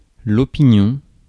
Ääntäminen
IPA: [ɔ.pi.njɔ̃]